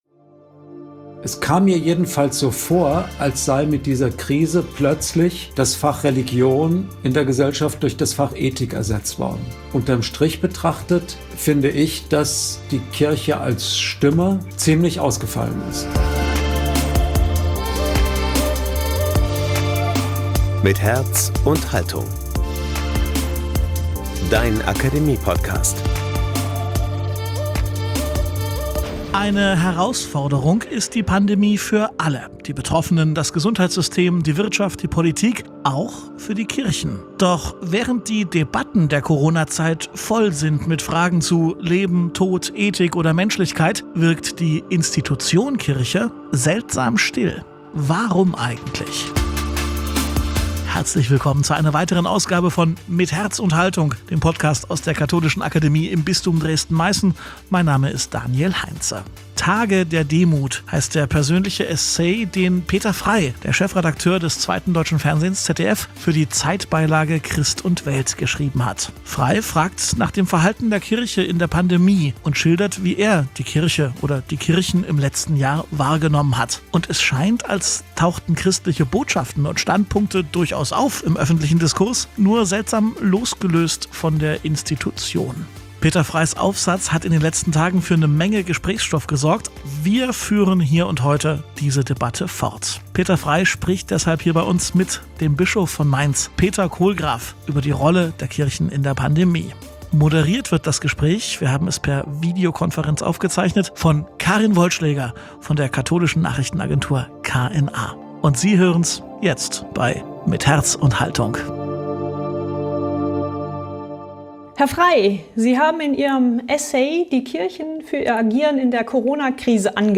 Der Bischof von Mainz, Dr. Peter Kohlgraf, spricht mit Dr. Peter Frey über die Rolle der Kirchen in der Pandemie.